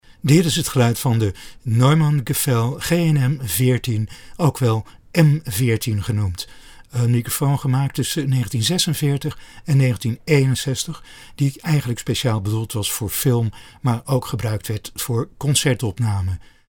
De M 14, of GNM 14 (Goose Neck Microphone), omnidirectionele condensator buizenmicrofoon met zwanenhals, was het vroegste naoorlogse nieuwe product van Neumann, hij werd gemaakt vanaf 1946, en gebruikte de befaamde M 7 capsule (gemaakt sinds 1932), met direct daarachter een RV12P2000 buis.
Neumann Gefell GNM 14 sound NL 2i2.mp3